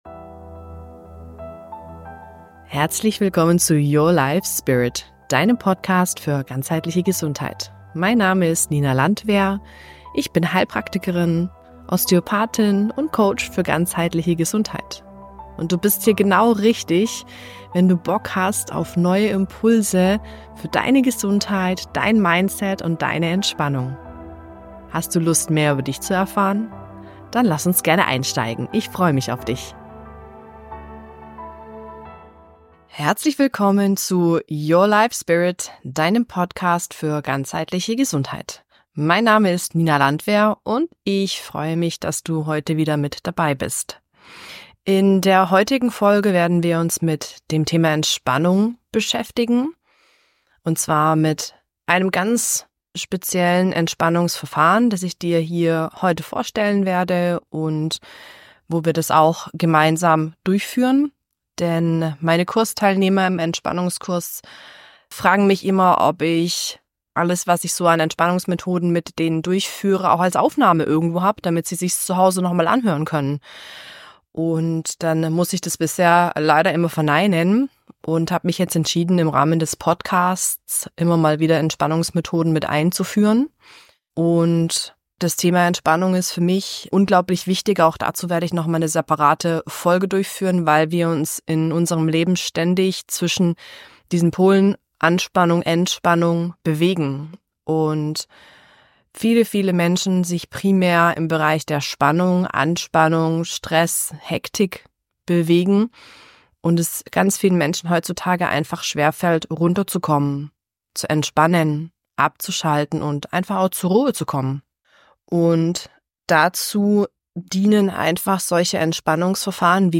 Es erwartet Dich eine geführte Übung, in der ich Dich Schritt für Schritt durch die PME leite. Du lernst, wie Du Deine Muskulatur gezielt an- und entspannst, um Spannungen zu lösen und Stress loszulassen.